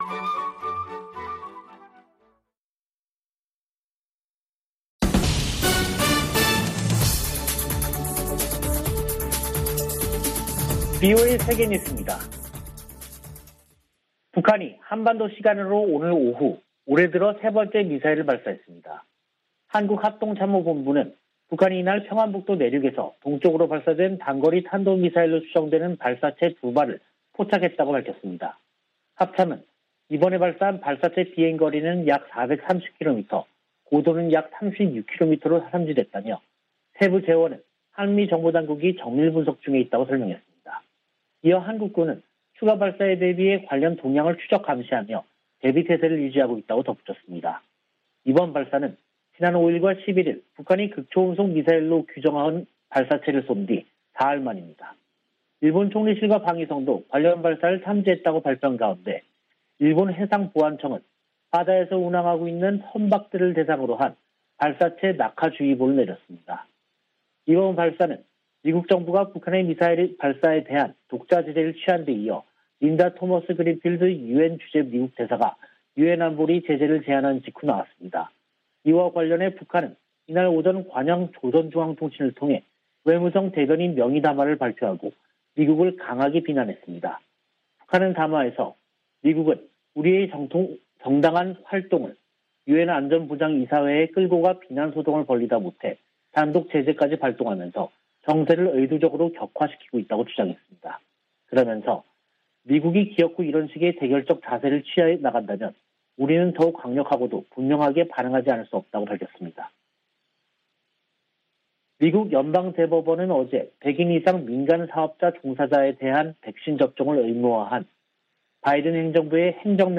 VOA 한국어 간판 뉴스 프로그램 '뉴스 투데이', 2022년 1월 14일 3부 방송입니다. 북한이 사흘 만에 또 단거리 탄도미사일로 추정되는 발사체 2발을 쐈습니다. 토니 블링컨 미 국무장관은 북한이 대화 제의에 미사일로 화답했다며, 책임을 물을 것이라고 강조했습니다. 북한의 미사일 부품 조달에 관여해 미국의 제재 명단에 오른 북한 국적자들이 유엔 안보리 제재 대상 후보로 지명됐습니다.